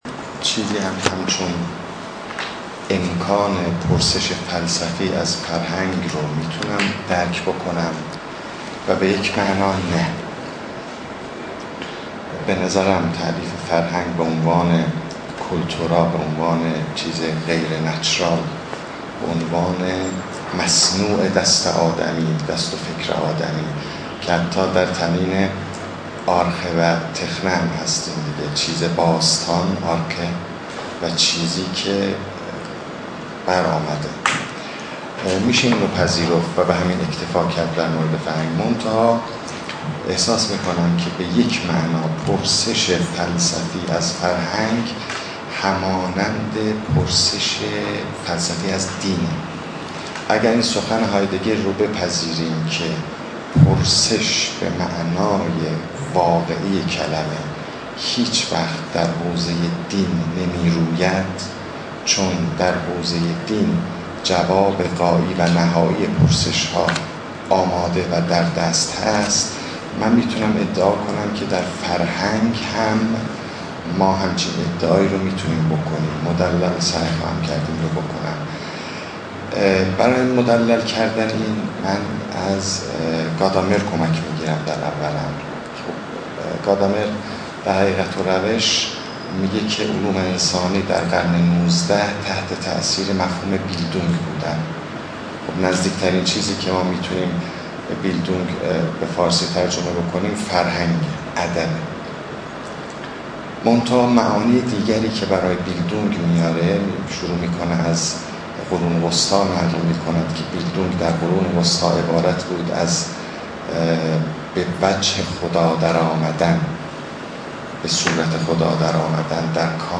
سخنرانی
در ششمین نشست از نشست‌های خانه اندیشمندان علوم انسانی